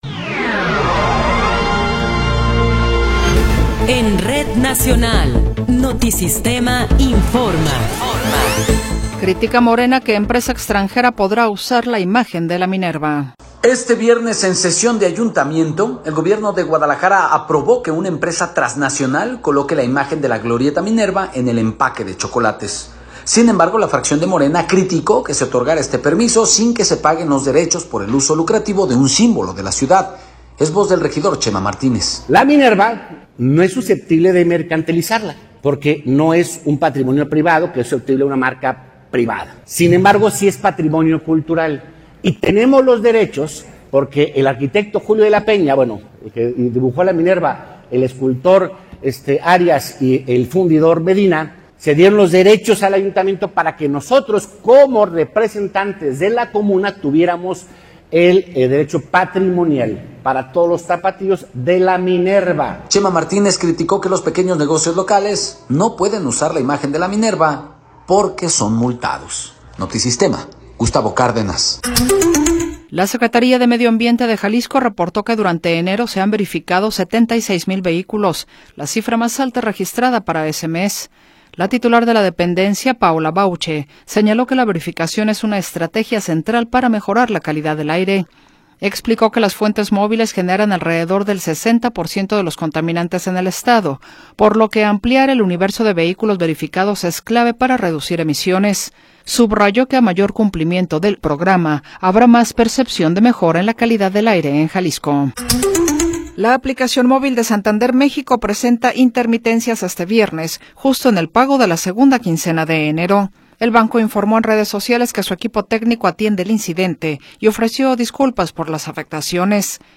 Noticiero 16 hrs. – 30 de Enero de 2026
Resumen informativo Notisistema, la mejor y más completa información cada hora en la hora.